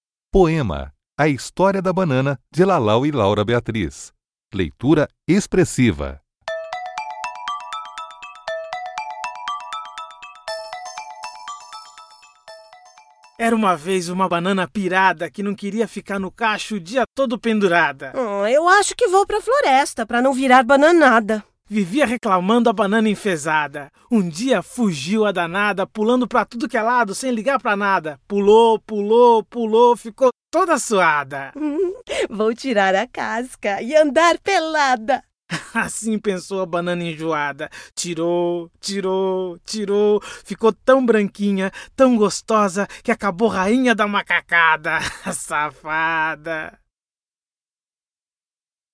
Poema "A história da banana" (leitura expressiva)
p_BUpor3_un03au_banana_expressiva.mp3